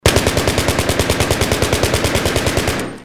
Play UMP Auto, Download and Share now on SoundBoardGuy!
ump-auto-indoors.mp3